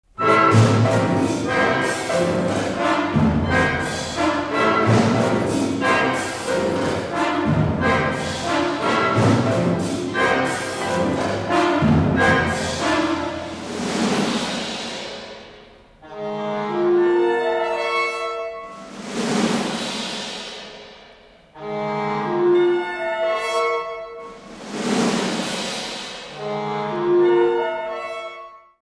How funny are those effects of yesterday's Theatre of Dread!